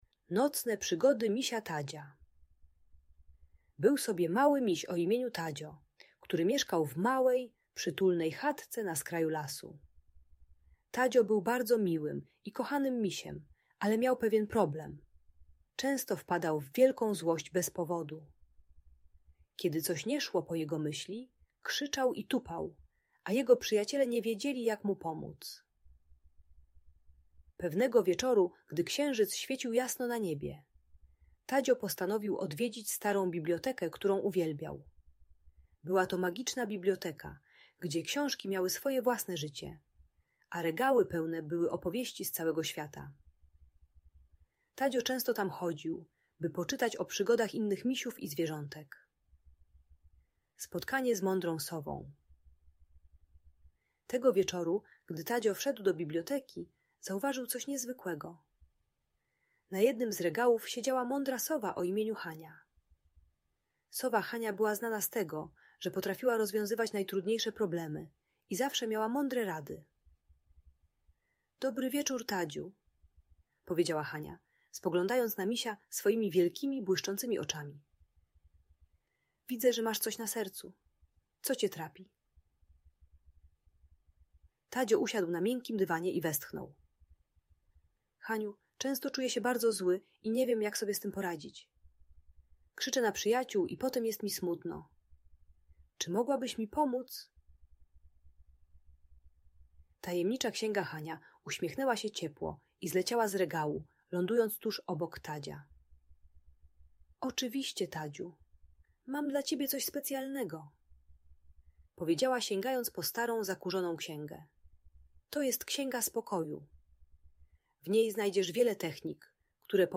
Nocne Przygody Misia Tadzia - Audiobajka